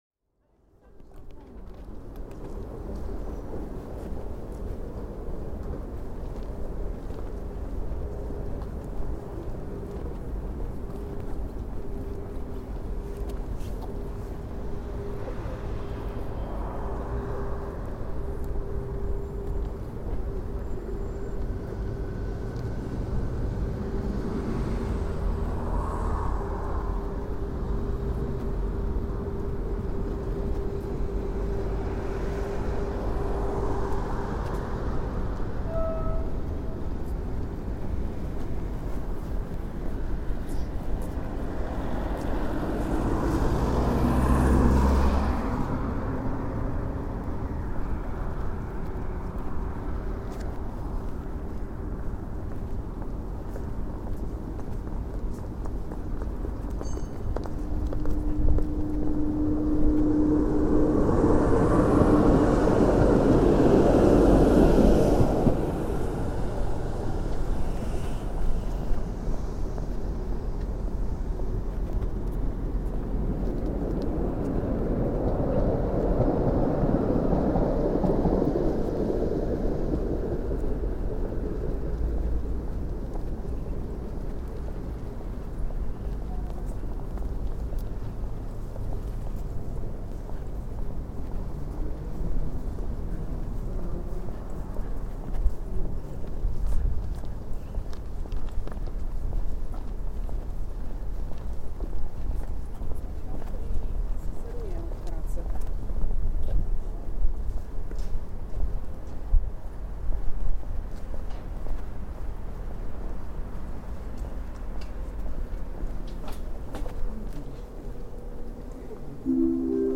Walking into and around Warsaw’s Eastern railways station, including tannoy announcement.